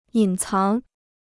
隐藏 (yǐn cáng): to hide; to conceal.